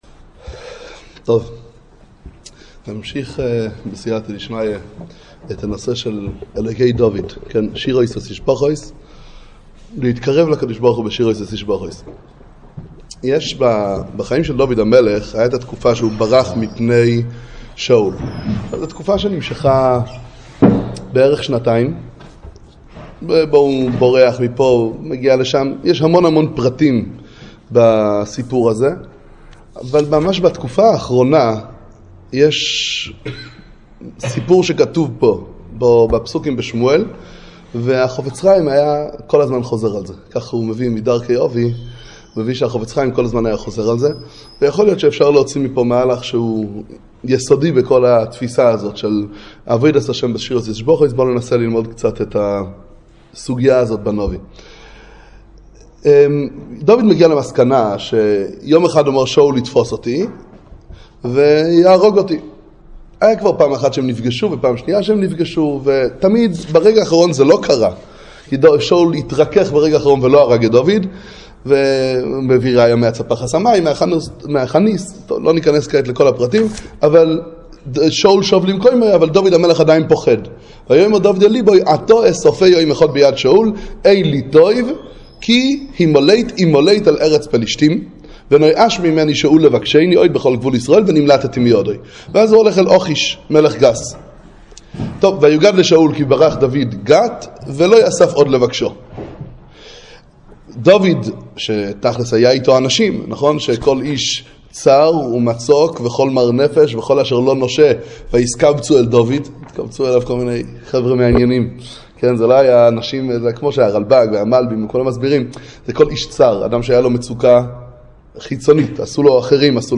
שיעור שלישי. המשך מהשיעורים הקודמים בנושא עבודת השם בשירות ותשבחות.